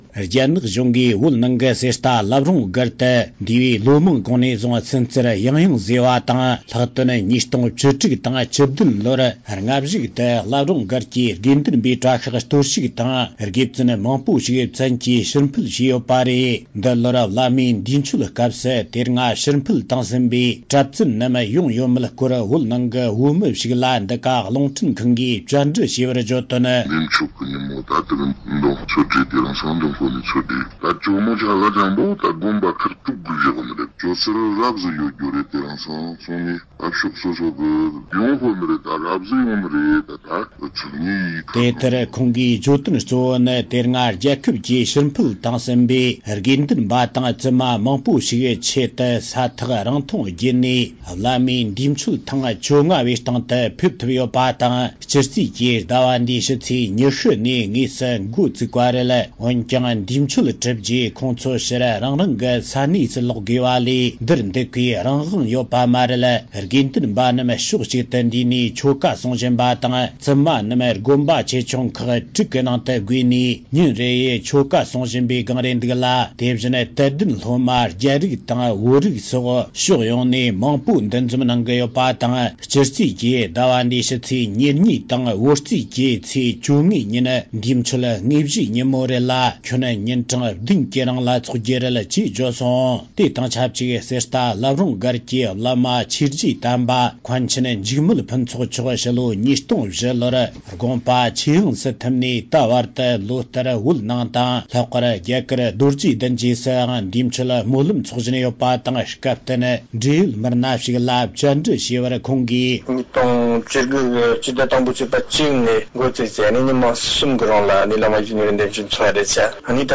བལ་ཡུལ་ནས་བཏང་བའི་གནས་ཚུལ་ཞིག་ལ་གསན་རོགས།